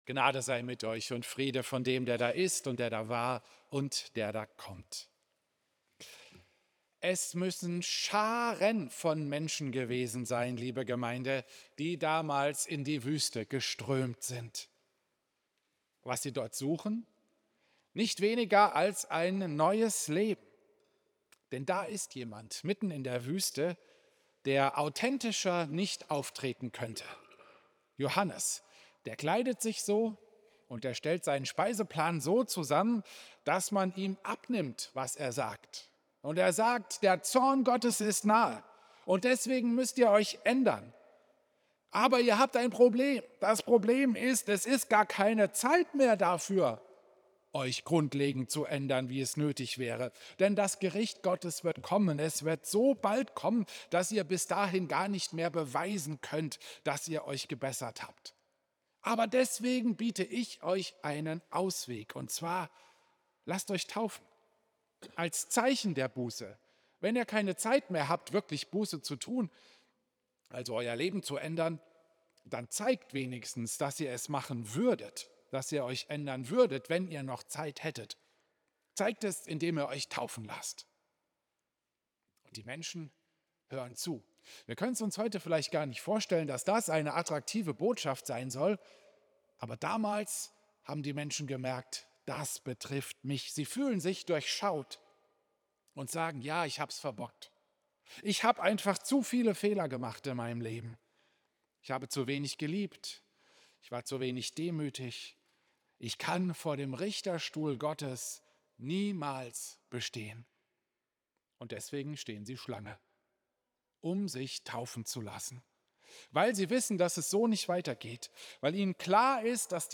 Klosterkirche Volkenroda, 11. Januar 2026